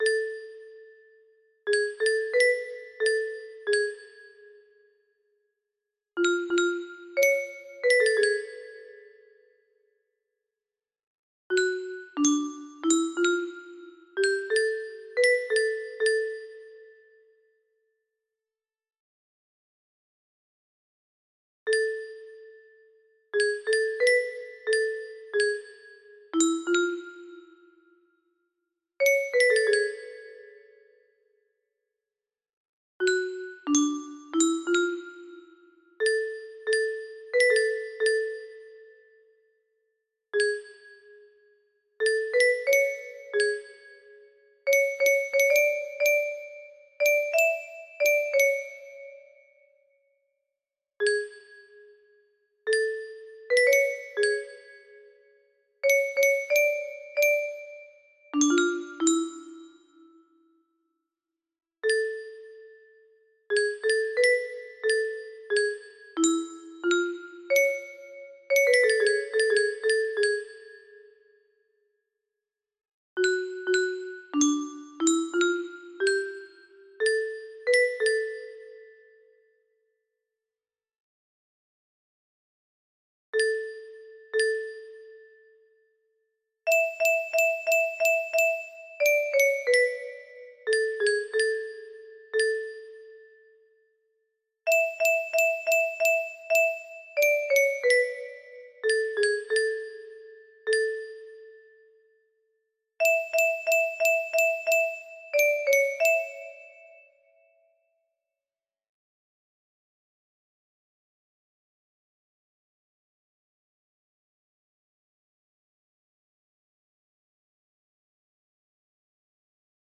Specially adapted for 30 notes